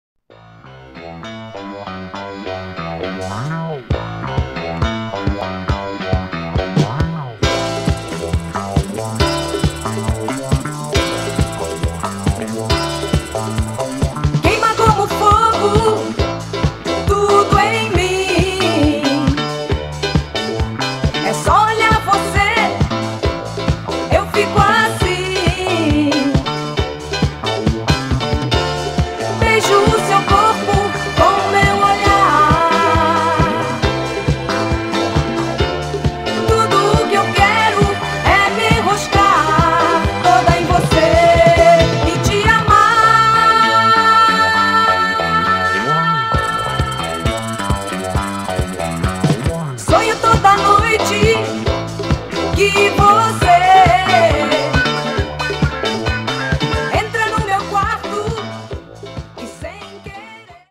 Killer set of Brazilian boogie & disco